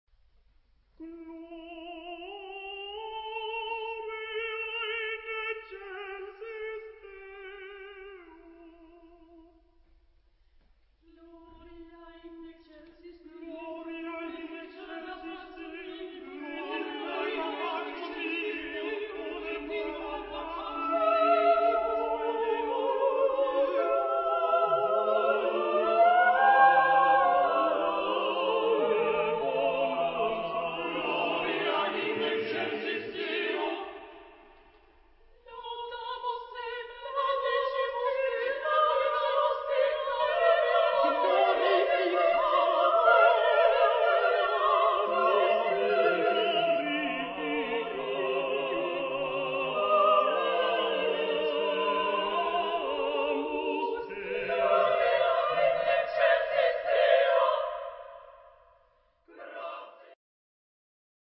Época : Siglo 20
Tipo de formación coral: SSATB + SATB  (9 voces Coro mixto )